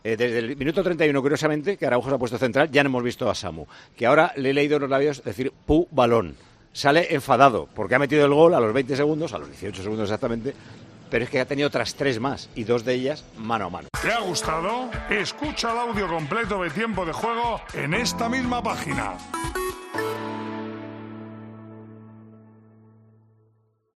Paco González reacciona al cambio táctico de Xavi durante el partido ante el Alavés: Curiosamente